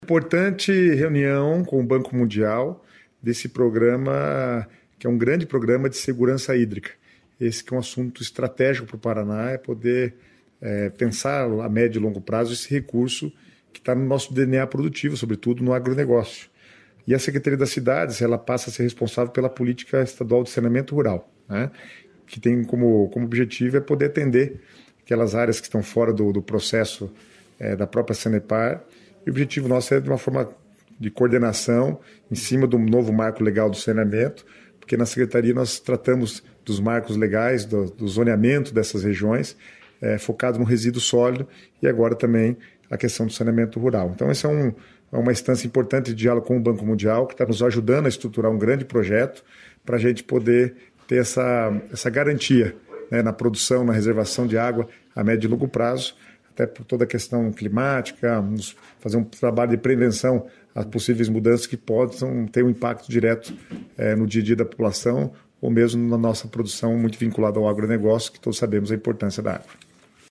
Sonora do secretário Estadual das Cidades, Guto Silva, sobre as reuniões do Governo com o Banco Mundial pelo Programa de Segurança Hídrica